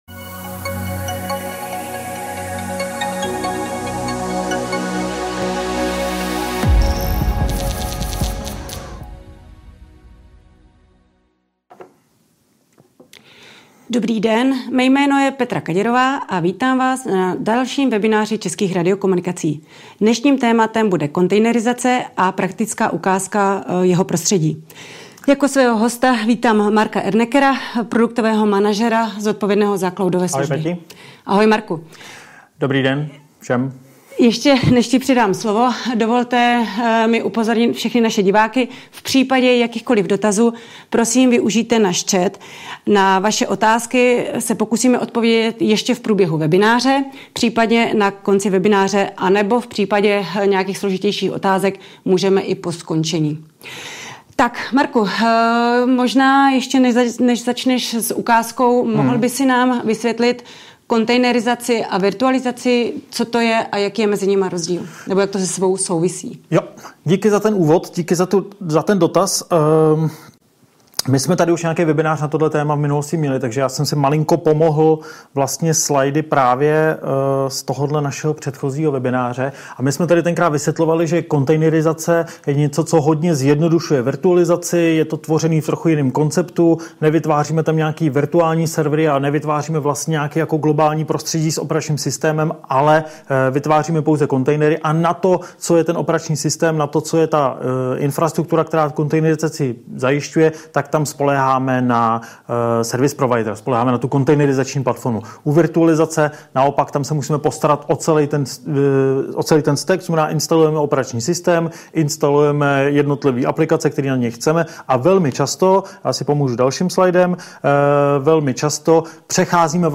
Videozáznam Audiozáznam Audiozáznam Textový záznam 00:00:13 Dobrý den